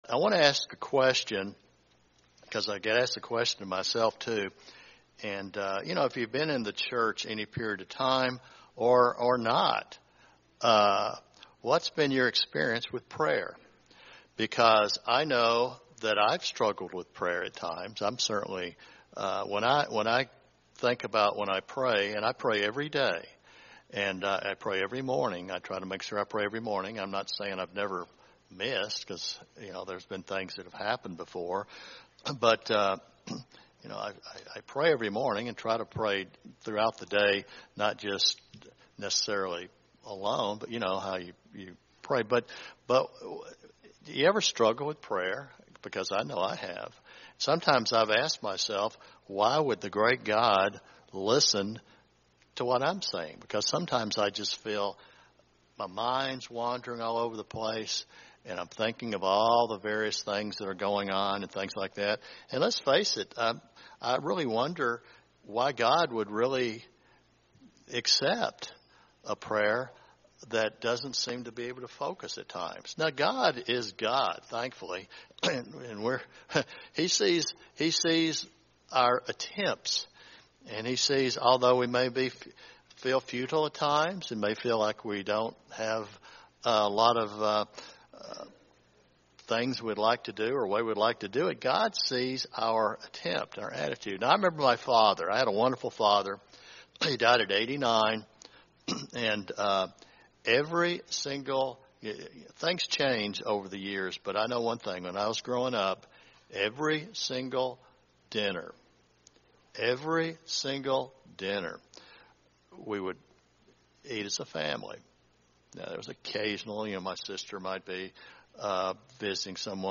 This sermon examines steps in prayer toward developing a closer walk with God.
Given in Dallas, TX